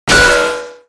acannonimpactmetala02.wav